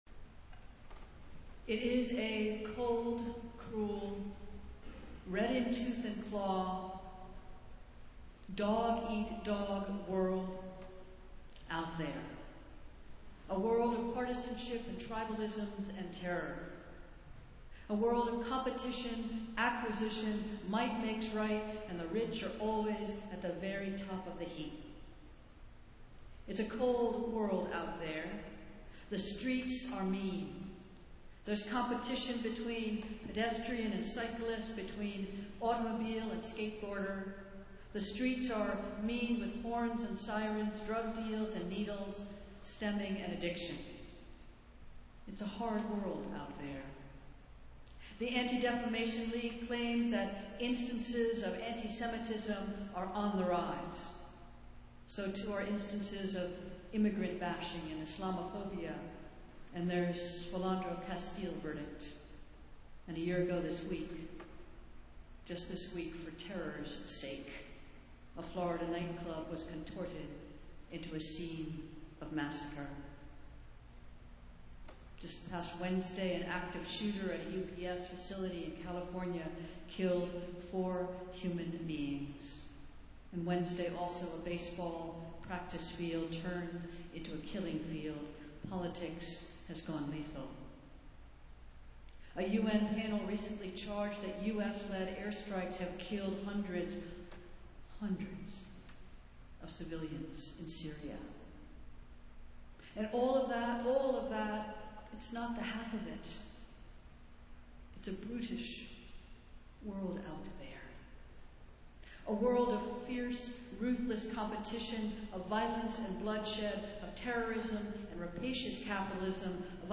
Festival Worship - Second Sunday after Pentecost